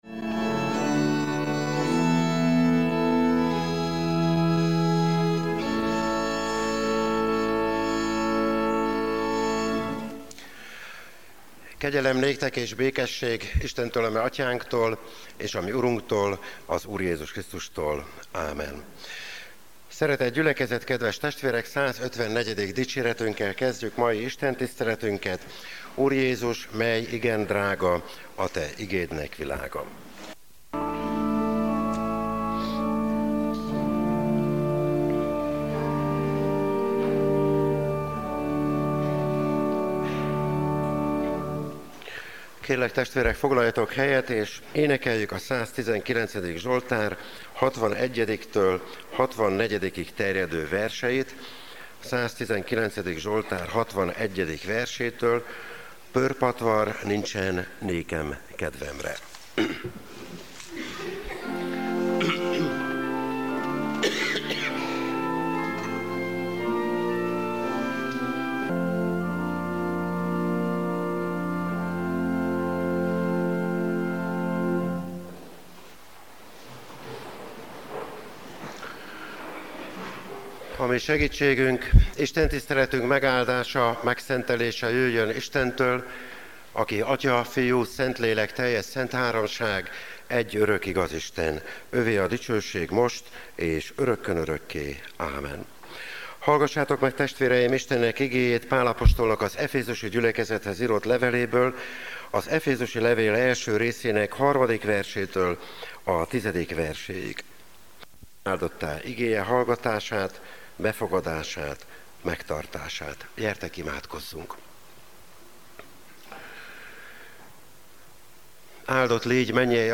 Lekció: Pál levele az efézusiaknak 1. rész 3-10. versek